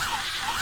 ZIPPER_Loop_01_loop_mono.wav